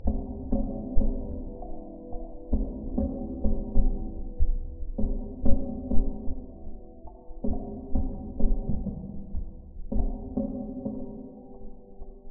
ambient_song_slow.mp3